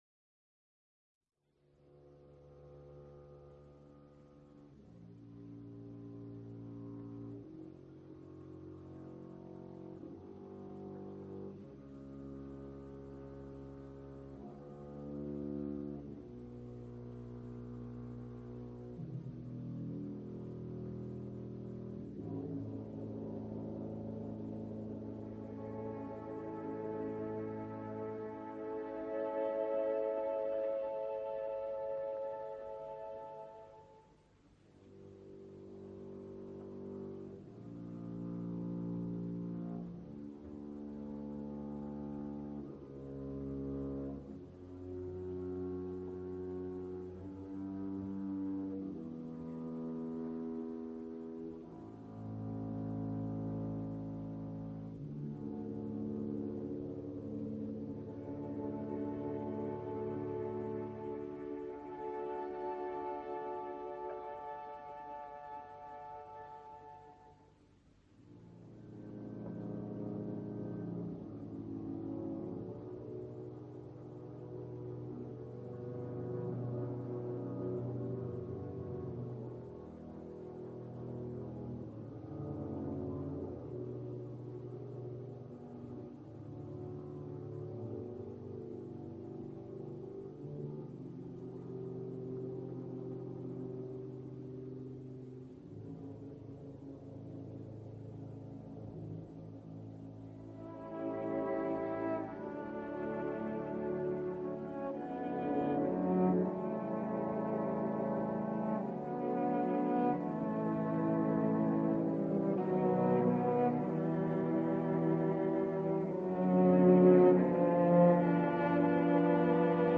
Orchestra della RAI di Torinodiretta da Angelo Questa.